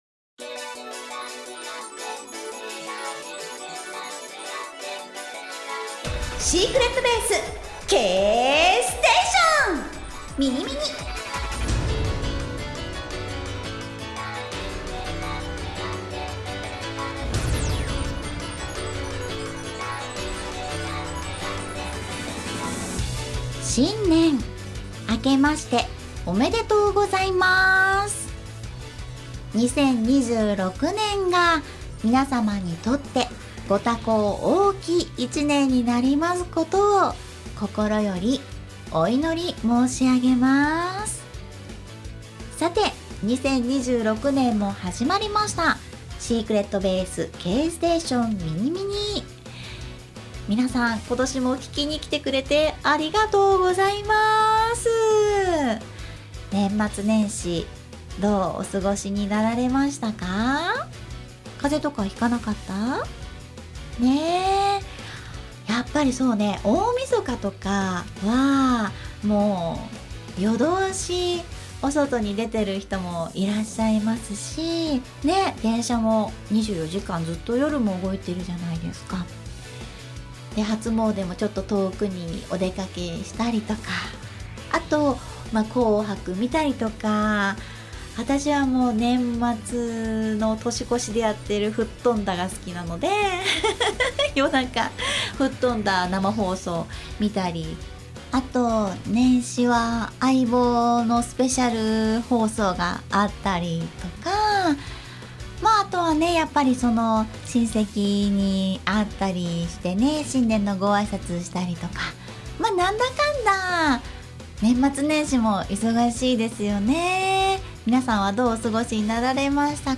アーティスト、声優がパーソナリティを務める様々な企画番組。